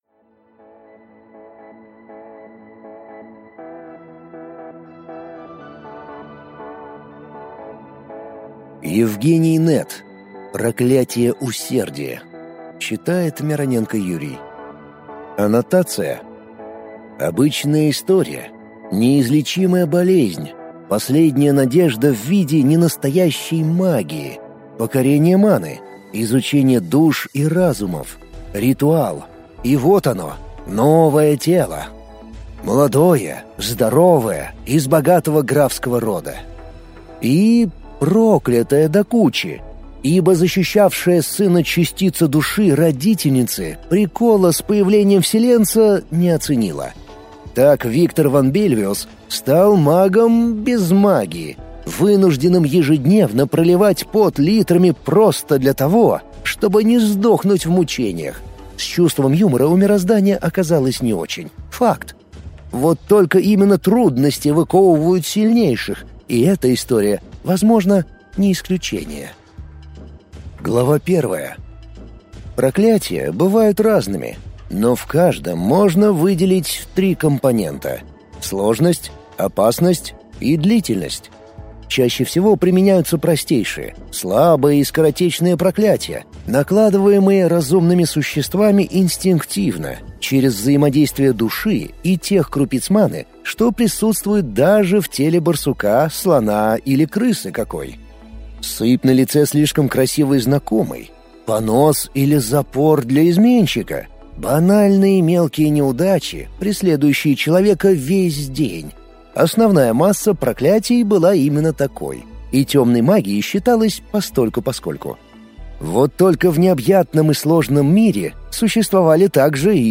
Аудиокнига Проклятие Усердия | Библиотека аудиокниг